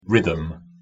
Po pierwsze, dodajemy dwa razy H. Po R i po T. Po drugie, pierwszego H nie czytamy, a drugie H występuje w głosce TH, czyli wystawiamy język między zęby i wypuszczamy powietrze.
pronunciation_en_rhythm.mp3